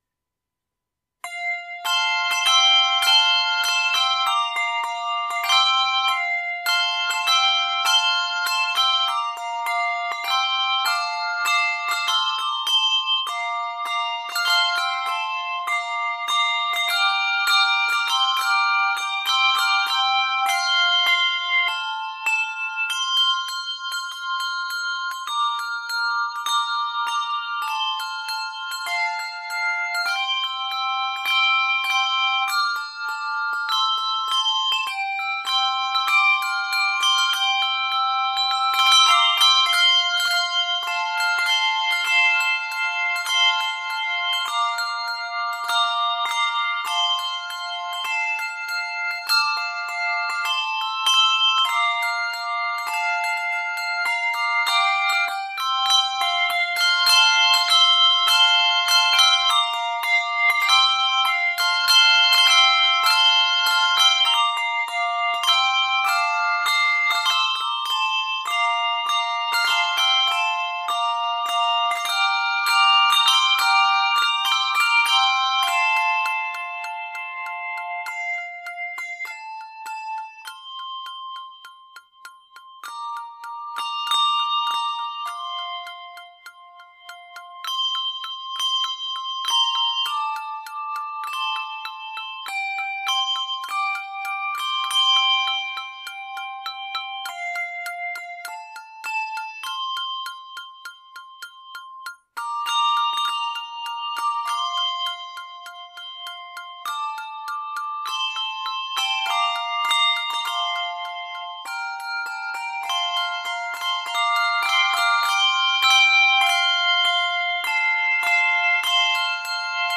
joyous setting